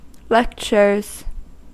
Ääntäminen
Ääntäminen US Haettu sana löytyi näillä lähdekielillä: englanti Käännöksiä ei löytynyt valitulle kohdekielelle. Lectures on sanan lecture monikko.